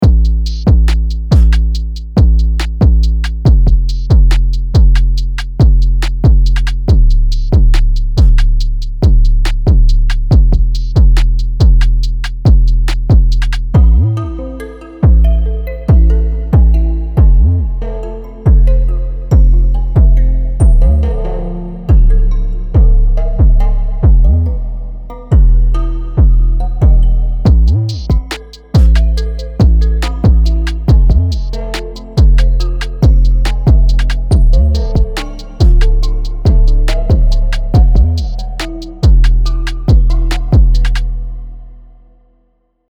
Get the authentic Atlanta trap sound with Atlanta 808 by TXVXN – a powerful VST plugin featuring hard-hitting 808s and get your hip-hop hits!
Atlanta 808 by TXVXN is a VST plugin designed to deliver the iconic 808 sound with a modern and aggressive twist, inspired by the signature sound of Atlanta, known for its dominance in trap and hip-hop music.